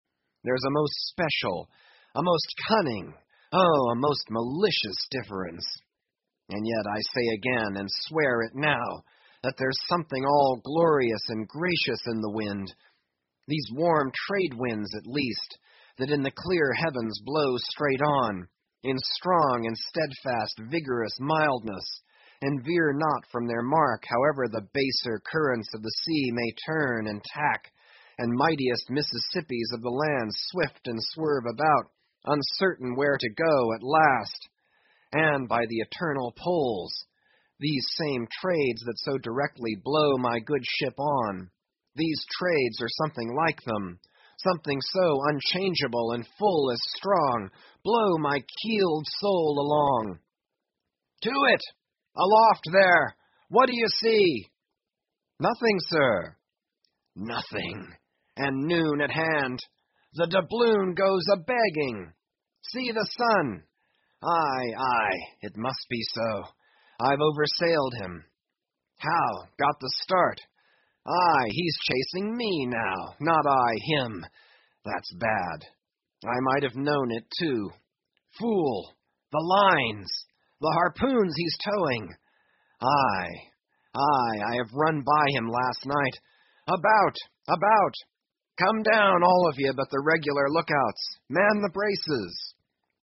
英语听书《白鲸记》第1033期 听力文件下载—在线英语听力室